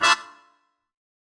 GS_MuteHorn-Dmin9.wav